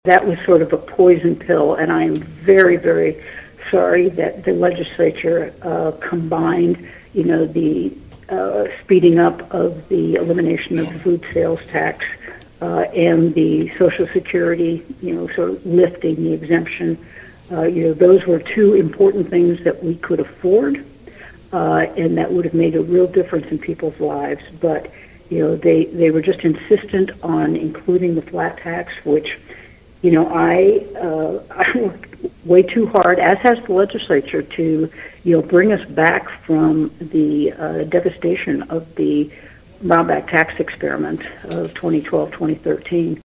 Despite certain policy disappointments, Governor Kelly touts positive work of 2023 legislative session on KVOE Morning Show